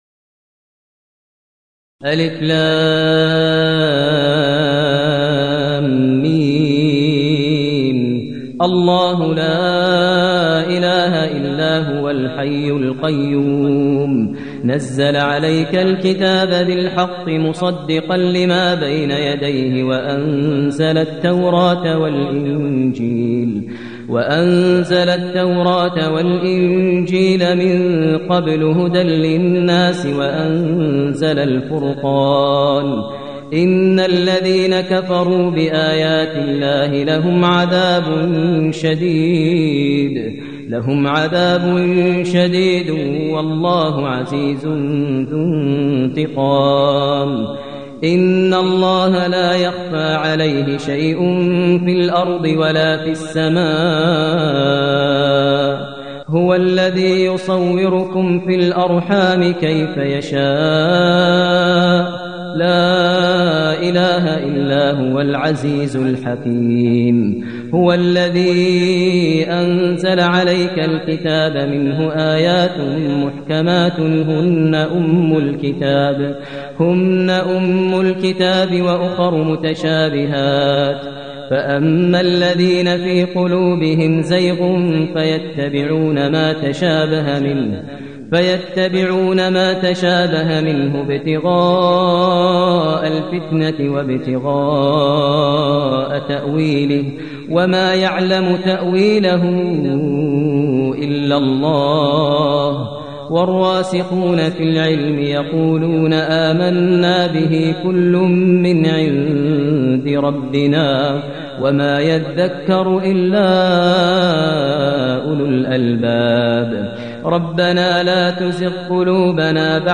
المكان: المسجد النبوي الشيخ: فضيلة الشيخ ماهر المعيقلي فضيلة الشيخ ماهر المعيقلي آل عمران